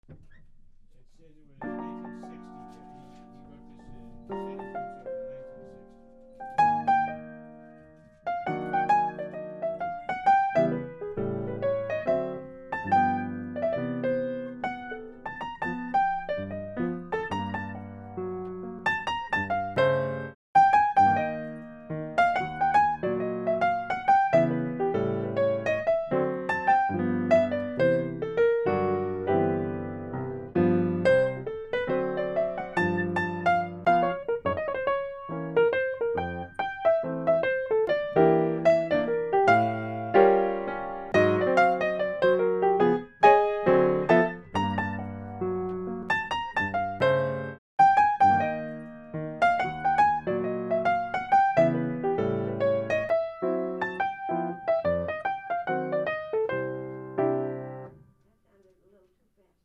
with an impressionistic sound
at a medium swing tempo